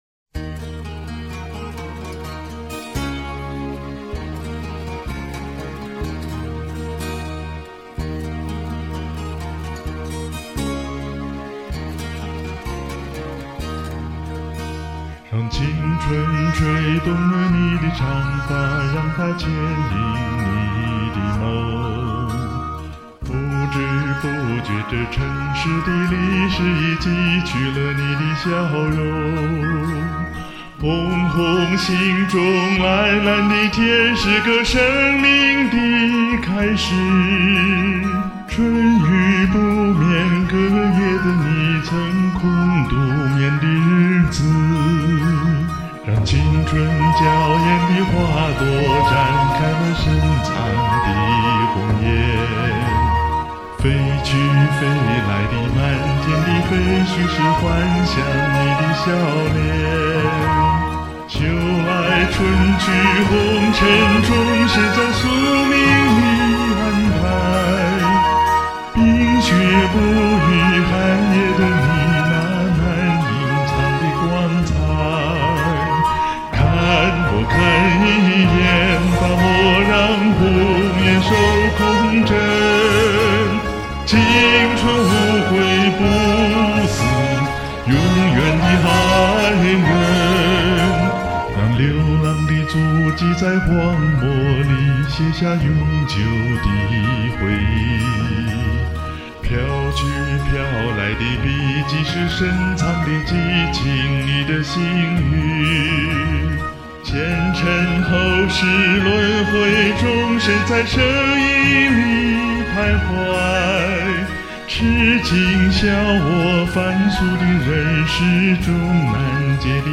借高山三周年年庆的机会，把刚上山时批量生产的歌重录一遍。